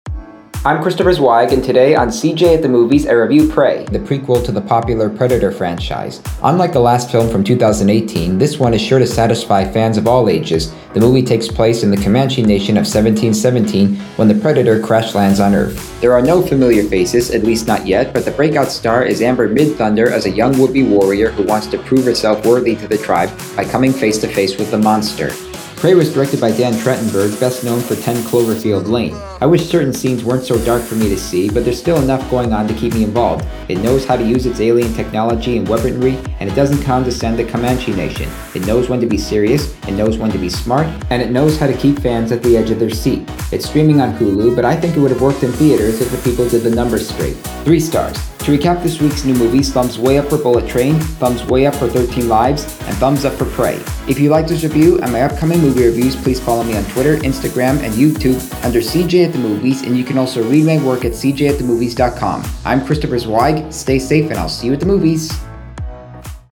Bullet Train, Thirteen Lives, and Prey Podcast Reviews